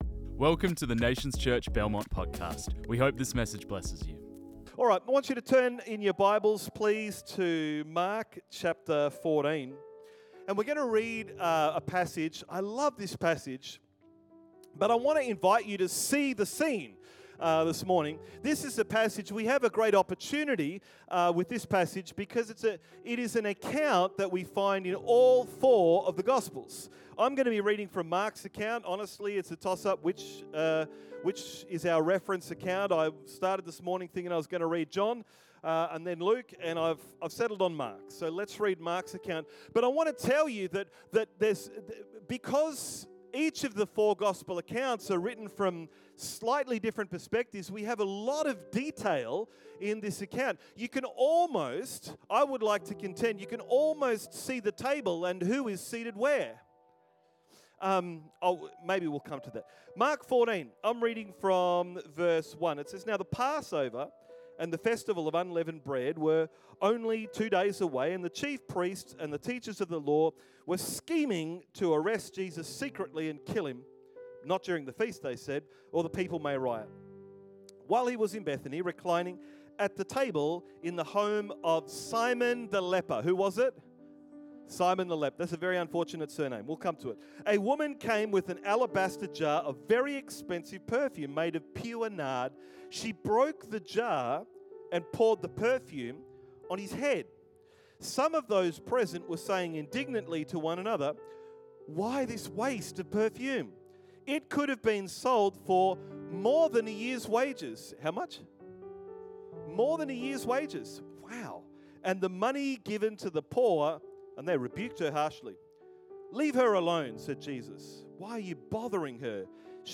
This message was preached on 11 May 2025.